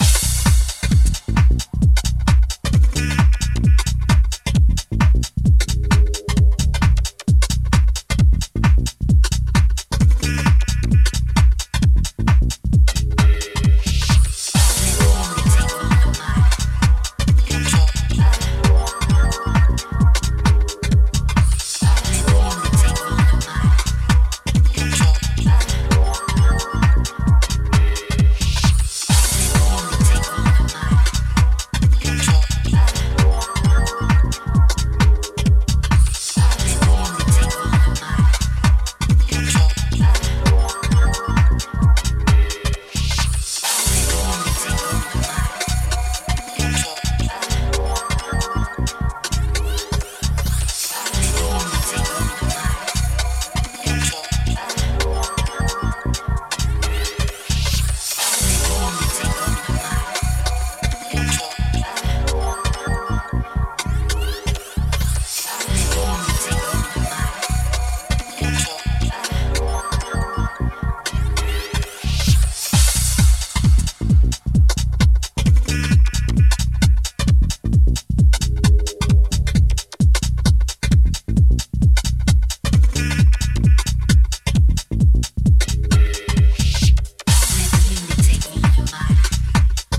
グルーヴ重視の構えで迎え撃っております。